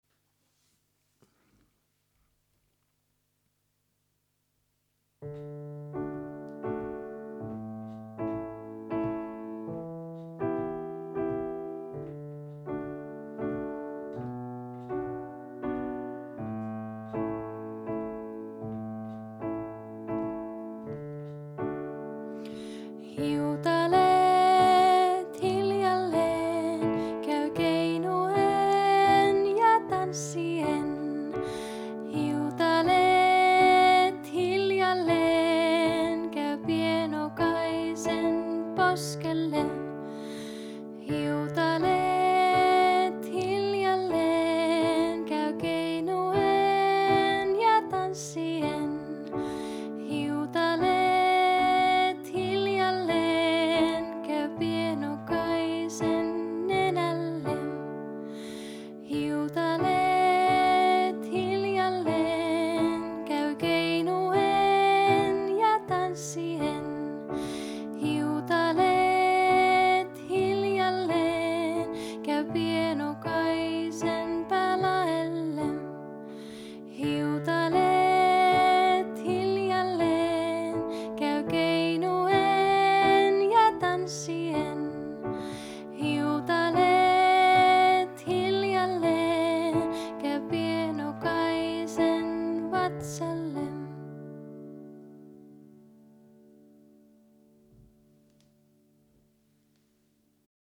Olen äänittänyt lauluni laulaen ja soittaen joko 5-kielisellä kanteleella tai pianolla. Säveltämäni kappaleet ovat sävelletty myötäillen suomalaista kansanmusiikkiperinnettä, ja niissä on vivahteita länsimaalaisesta populaarimusiikista.